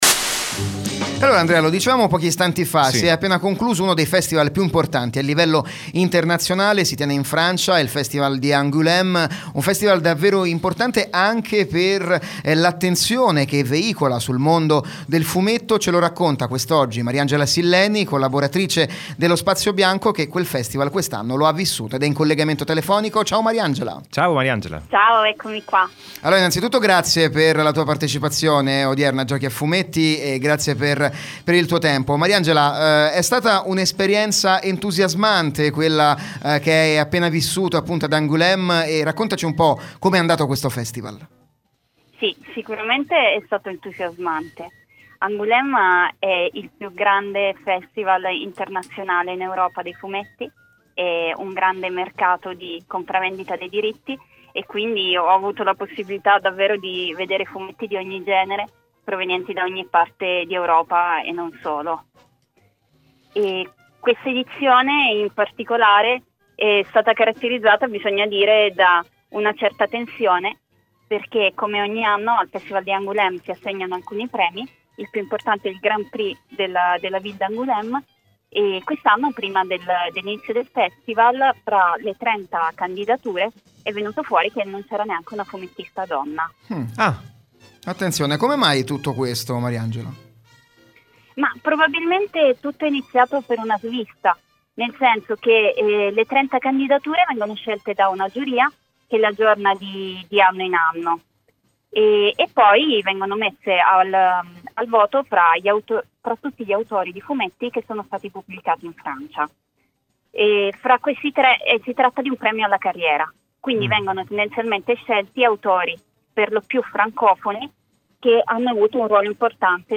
Lo Spazio Bianco è lieto di presentarvi il podcast di Giochi a Fumetti, la trasmissione radiofonica in onda ogni sabato su Radio Cusano Campus.